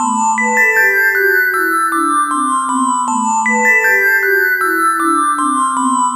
Tornado Chimes.wav